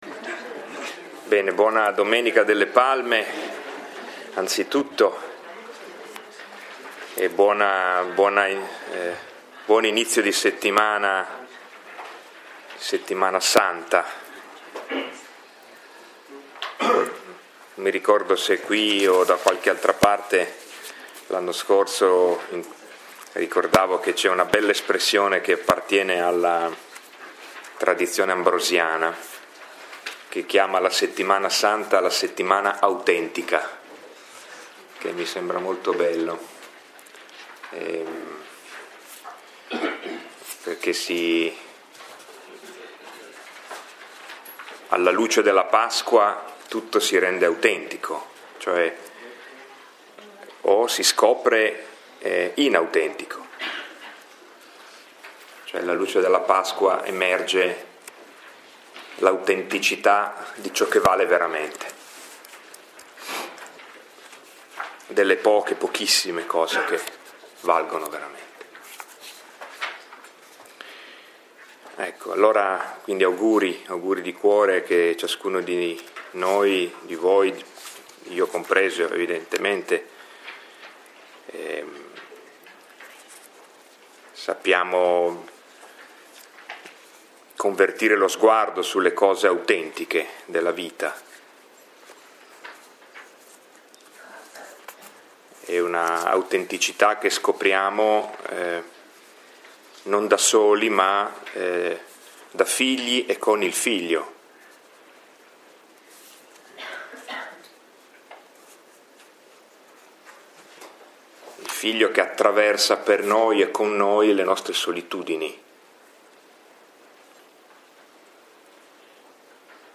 Lectio 6 – 20 marzo 2016